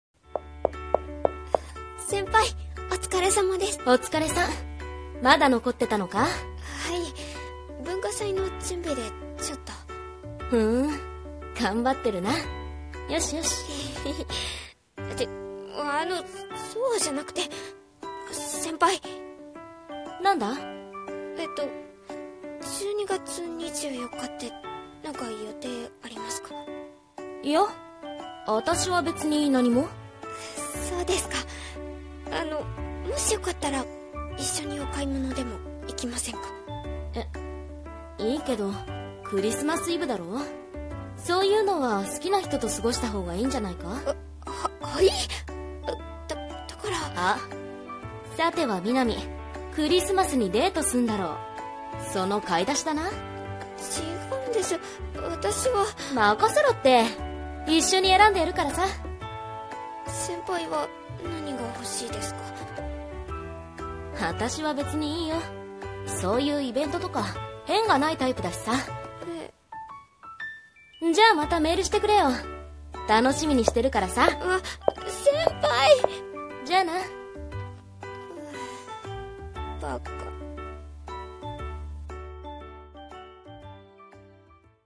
【コラボ声劇】一緒に過ごしたい日